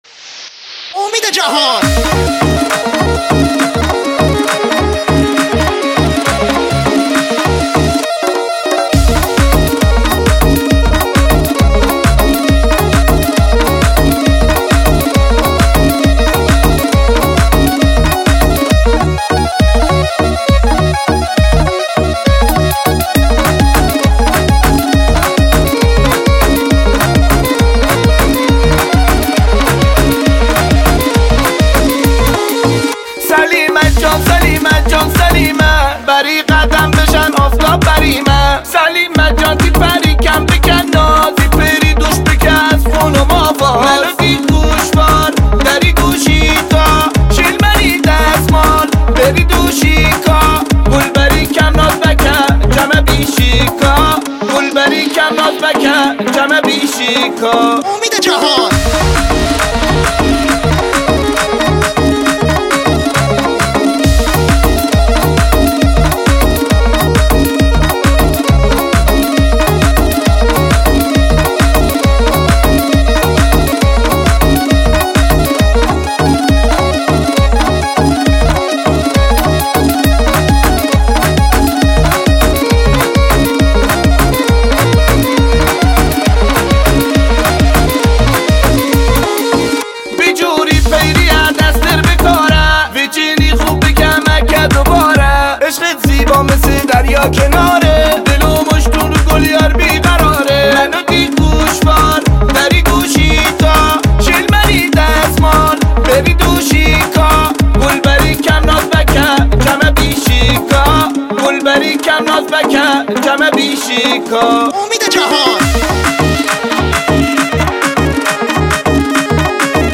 آهنگ های شاد ویژه شب یلدا